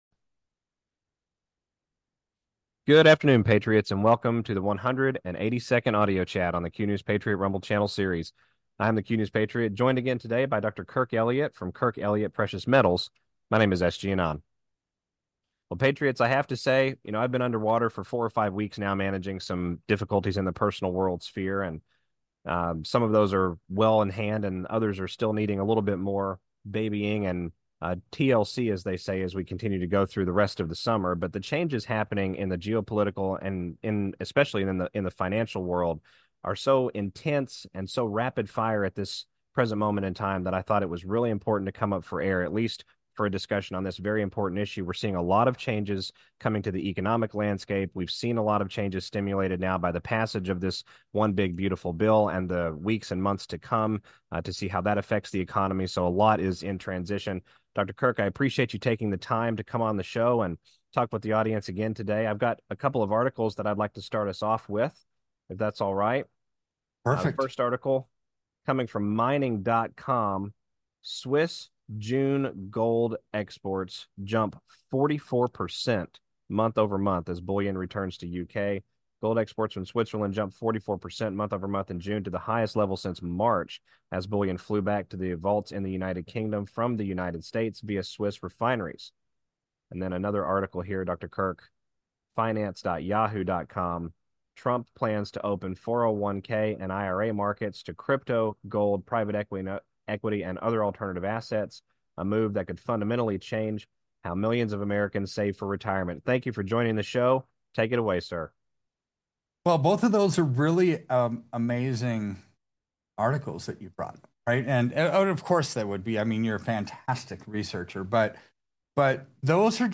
AUDIO CHAT 182